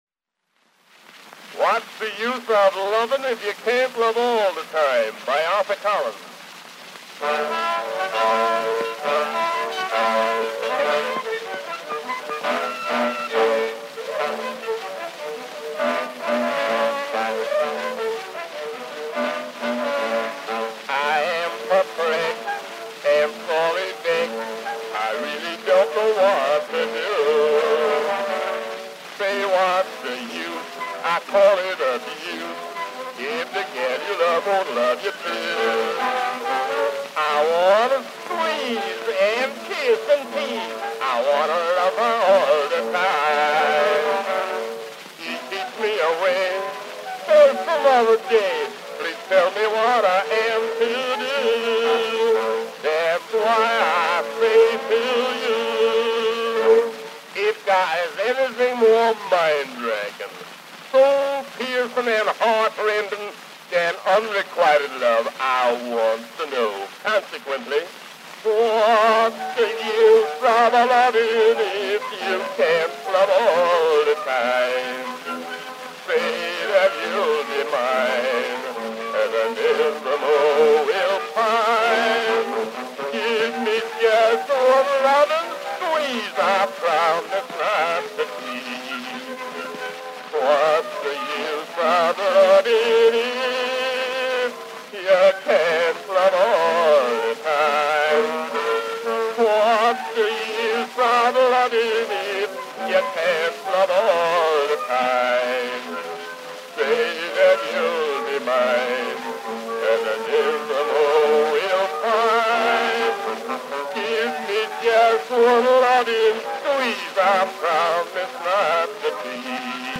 Baritone solo with orchestra accompaniment.
Popular music—1901-1910.